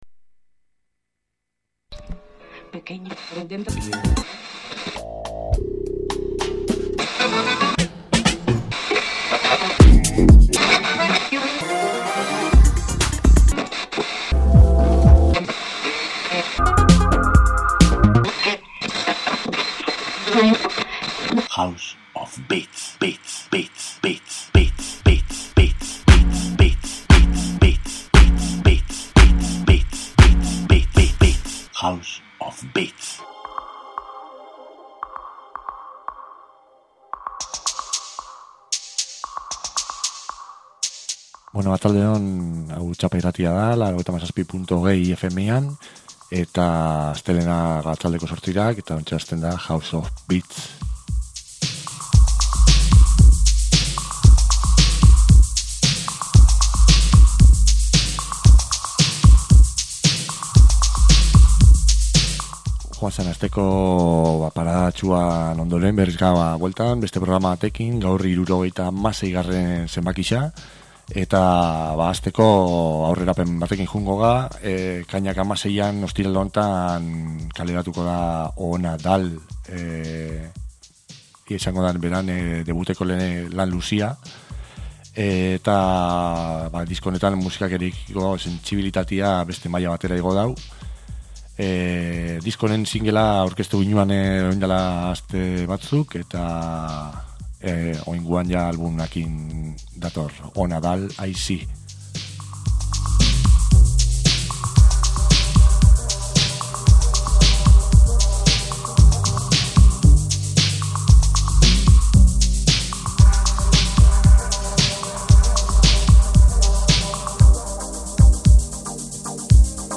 elektro doinuak
house abesti bat